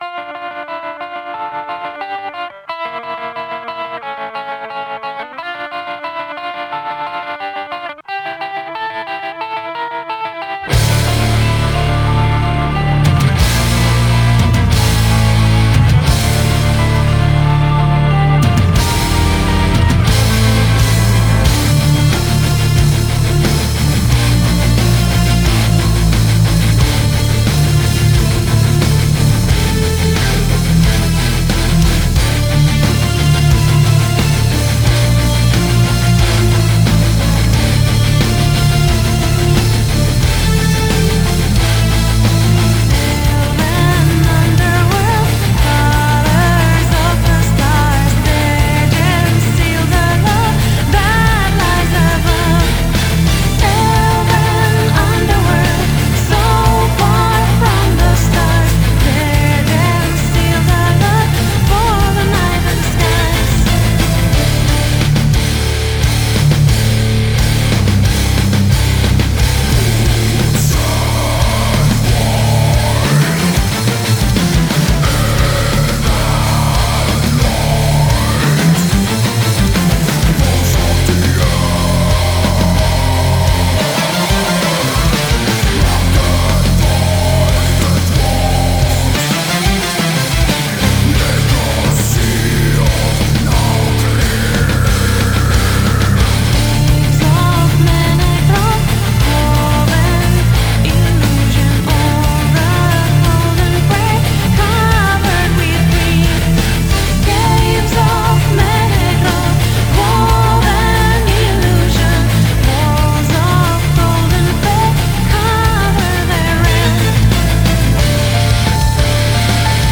Gothic Metal